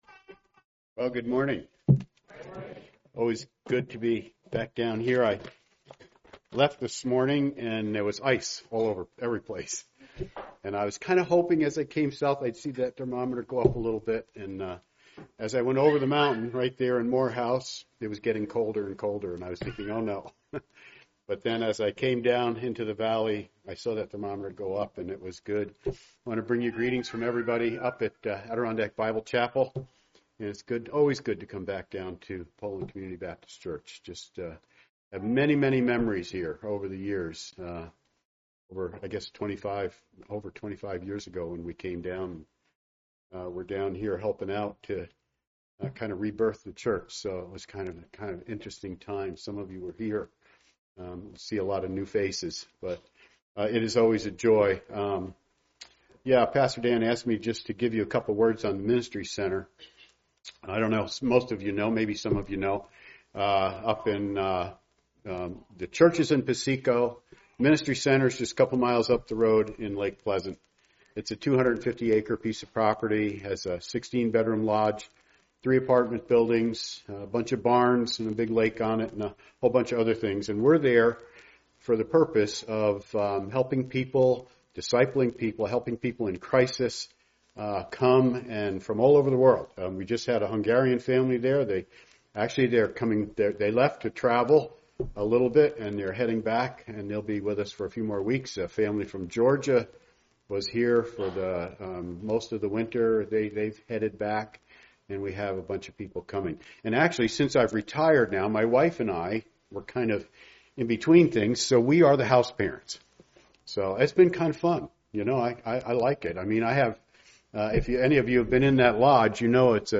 Part of the Guest Speakers series, preached at a Morning Service service.